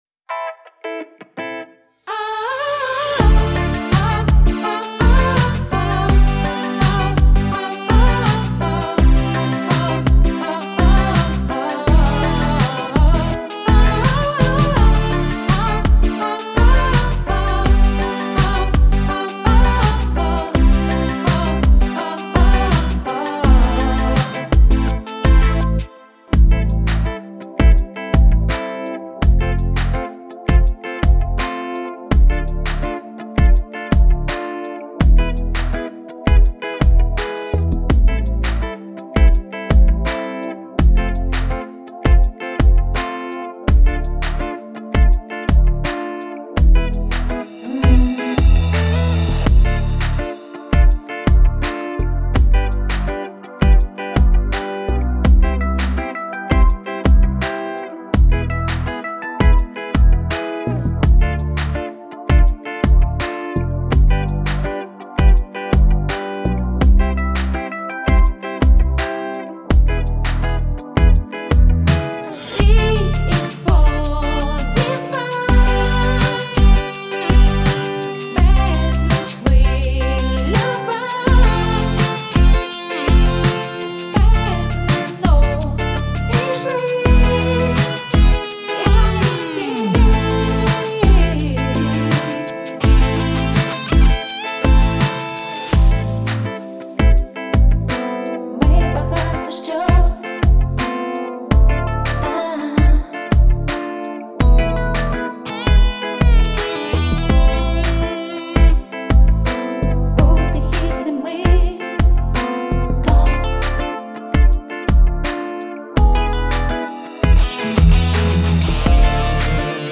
Багато гітари)